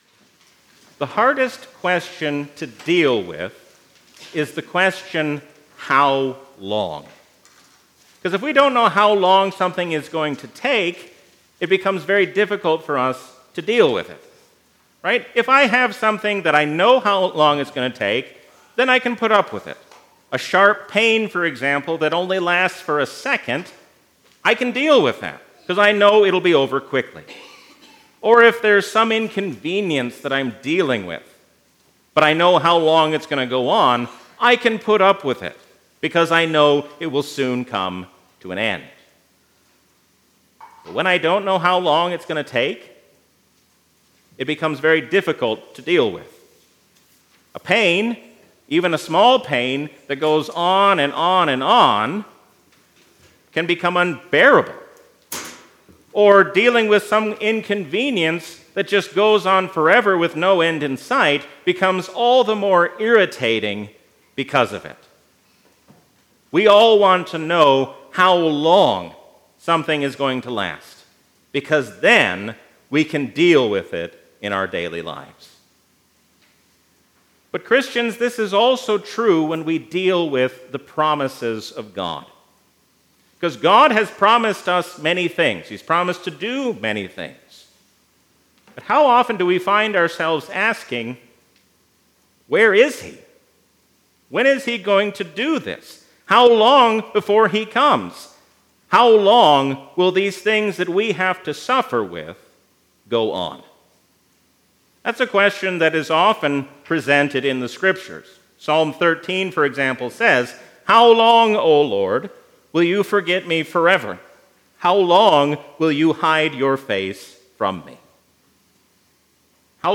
A sermon from the season "Trinity 2022." God is not wasting time making us wait for Him, so let us not waste time either.